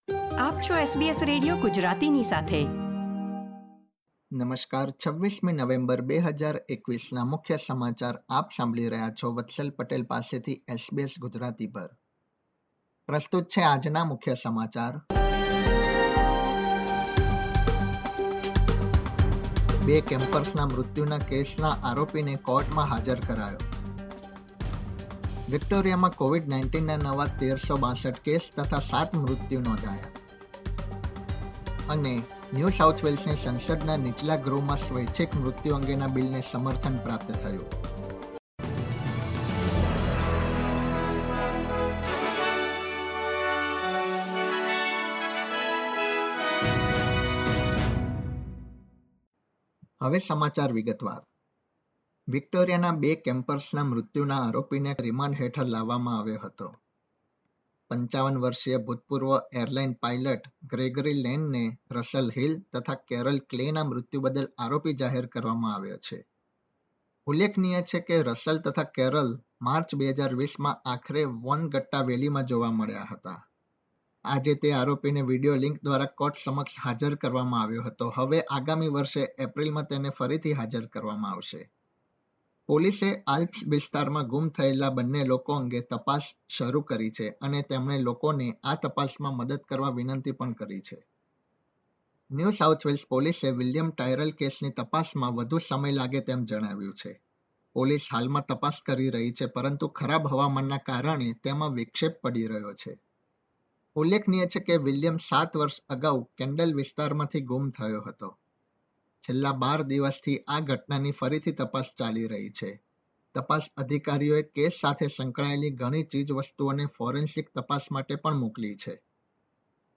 SBS Gujarati News Bulletin 26 November 2021
gujarati_2611_newsbulletin.mp3